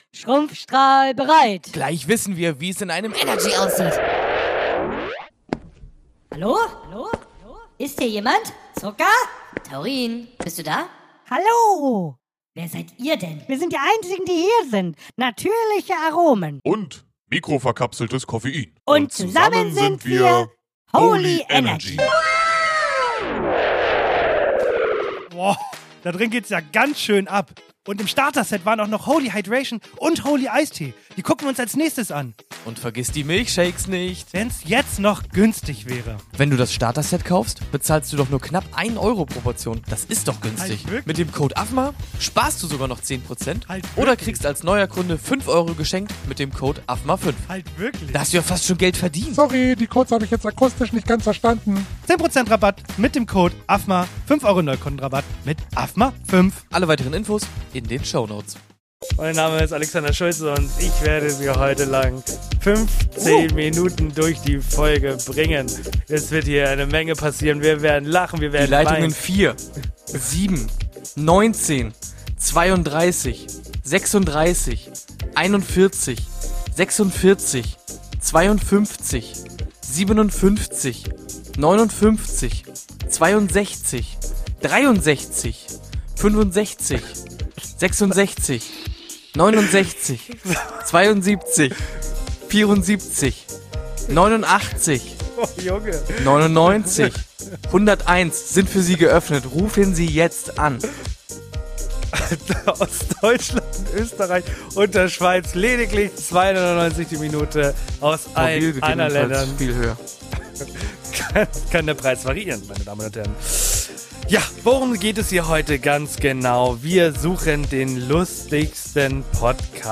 Ihr befindet euch mittendrin, wir lassen einfach laufen, sind ganz frei und zeigen euch, wie witzig eine ungescriptete 9Live Folge werden kann, wenn man einfach mal macht.